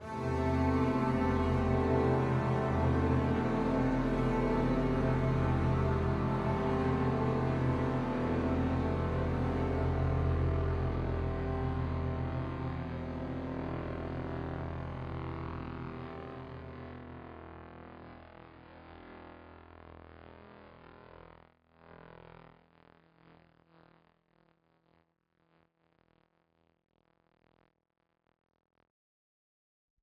The piece is rounded out by strings playing additional data from the infrared telescopic trio of ESA’s (European Space Agency’s) Herschel Space Telescope, NASA’s retired Spitzer Space Telescope, and NASA’s retired Wide Image Survey Explorer (WISE) as chords.